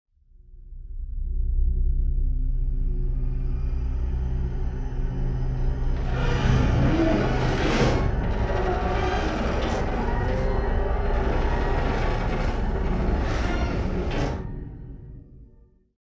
creepy-voice
wav formatlı korku sesi efekti